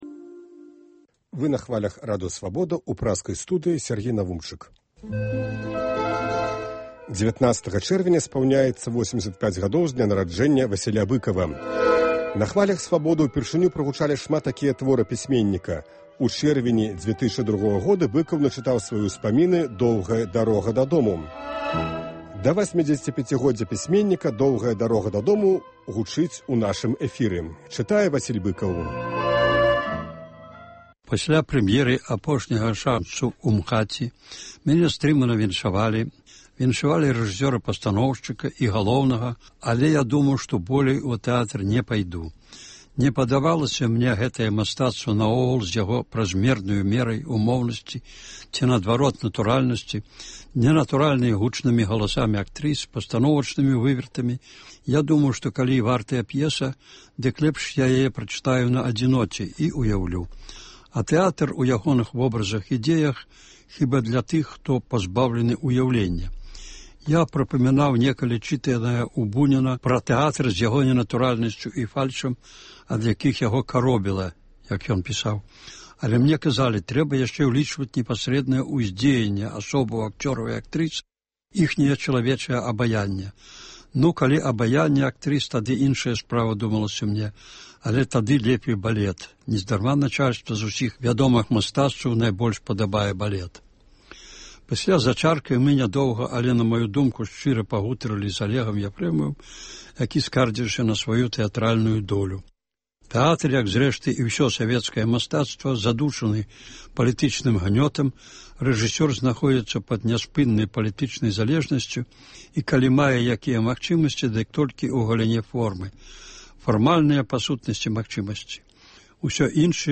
З архіву "Свабоды": Васіль Быкаў чытае "Доўгую дарогу дадому"